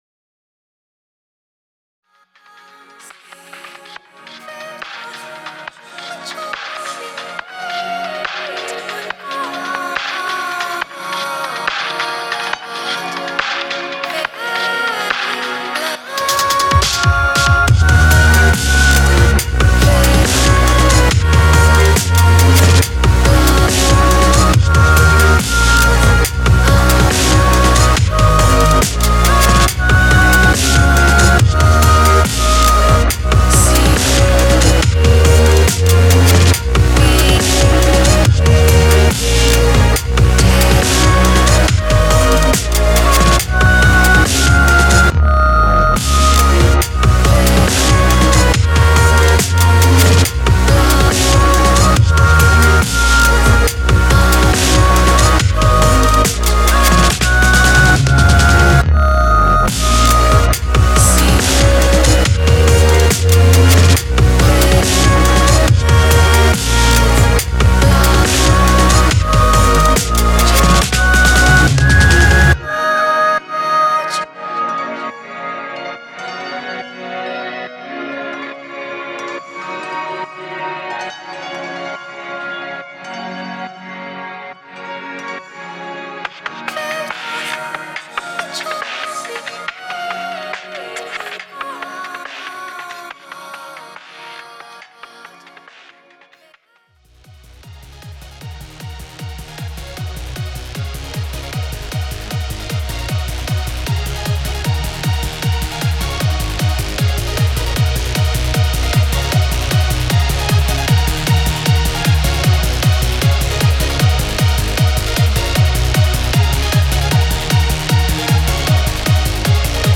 製作中のデモサウンドです。
2:25〜Last 「Code: Answer（仮）」ピアノ・メロディー版
一曲目はアンビエントでエフェクティブなピアノサウンドとハードなワブルベース、デジタリックに加工したリズムトラックを編集して製作中のエレクトロニカ「Synchronicity（仮）」。女性ボーカル音源を使用した神秘的で幻想的なサウンドの楽曲になっています。
二曲目はハイテンポなトランス「Cyber dolls（仮）」。テクノ・トランス系のサウンドを使用したメロディックでテンポが早めの楽曲になっています。
三曲目は歌モノのダブステップ曲「Code: Answer（仮）」。エレクトロ系のシンセサウンドを使用して編集中でメロディアスな楽曲になっています。